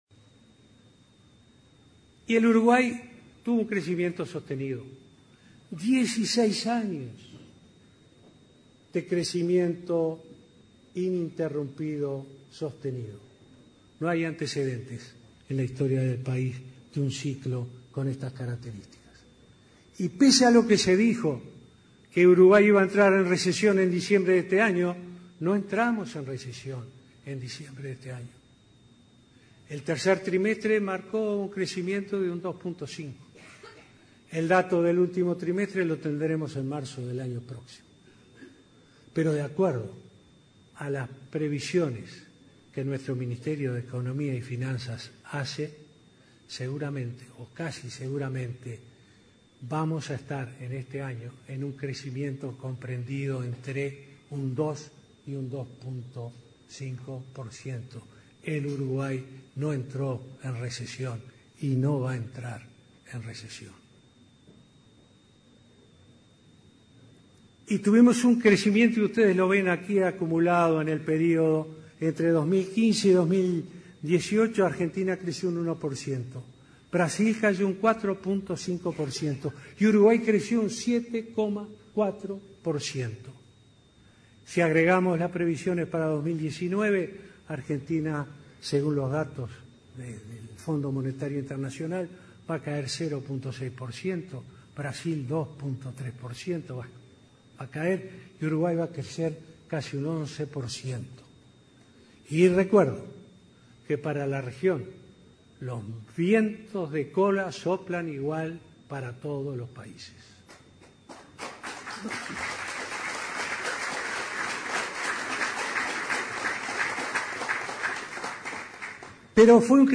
“Uruguay no entró ni entrará en recesión”, aseguró el presidente Vázquez en ADM